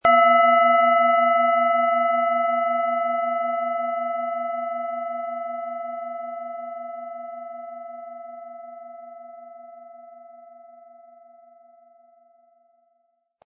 Planetenton 1
Die Schale, mit Lilith, ist eine in uralter Tradition von Hand getriebene Planetenklangschale.